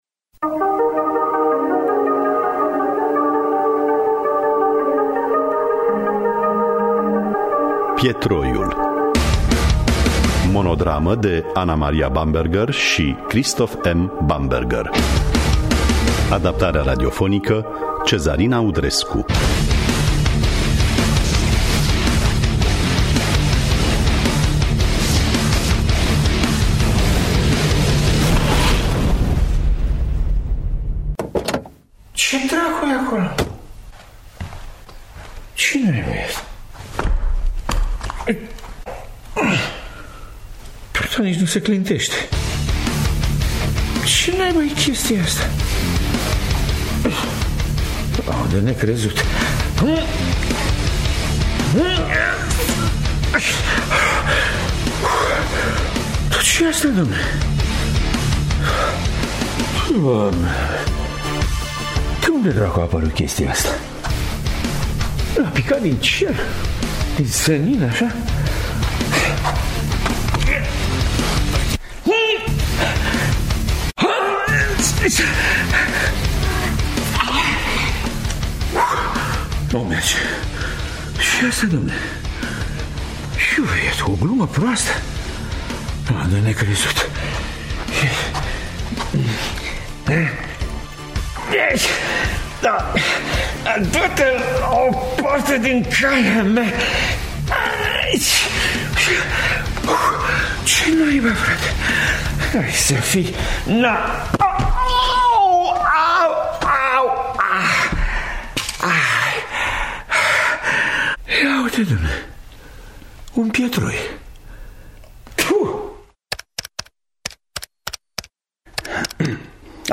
Adaptarea radiofonică
Interpretează: Petre Lupu.